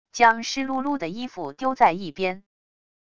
将湿漉漉的衣服丢在一边wav音频